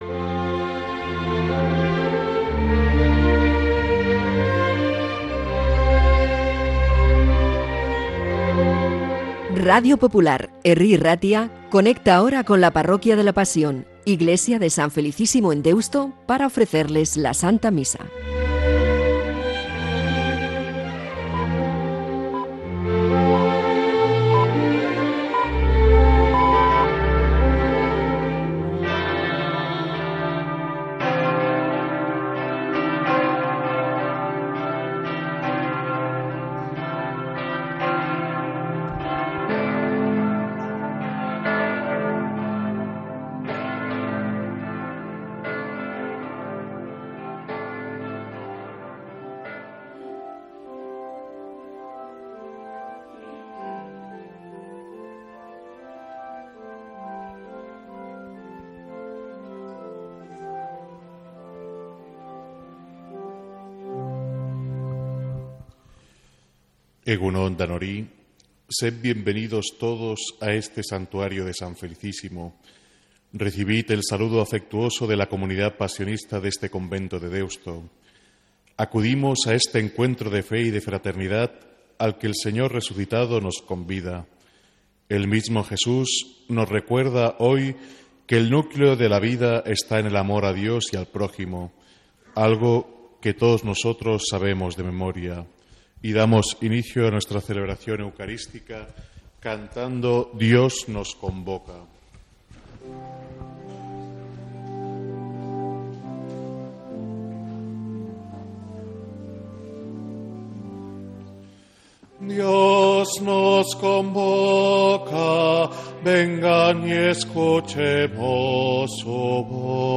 Santa Misa desde San Felicísimo en Deusto, domingo 3 de noviembre